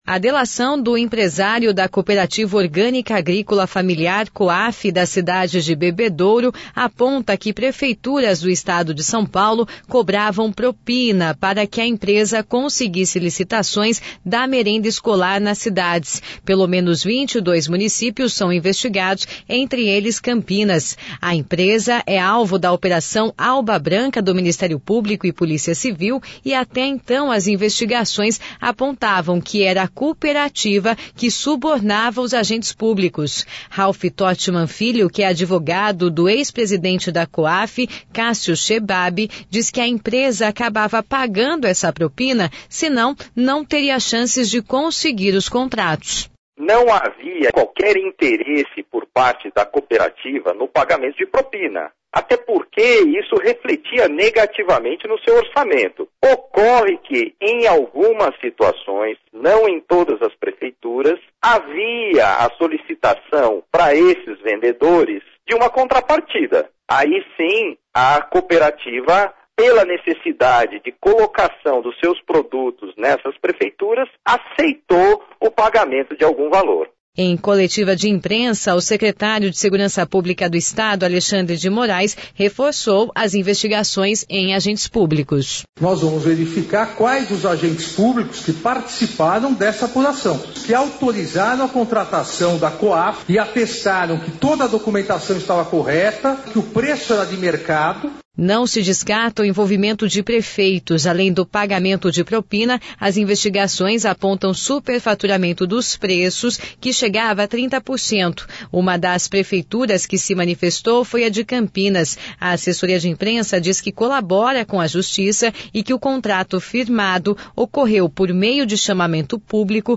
Em coletiva de imprensa, o Secretário de Segurança Pública do Estado, Alexandre de Moraes, reforçava as investigações de agentes públicos.